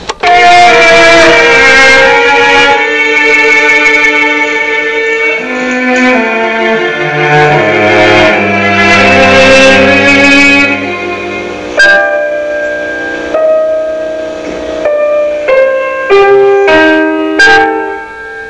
***This summer at Walden i composed a piece i liked a lot, Piano Trio No. 1. though the following sound bites are not the best quality, I still think you should try and listen to them.
HERE to hear a section in the semi beginning...when the piano is first introduced.
I am sorry for the quality, but hey, I'm not professional.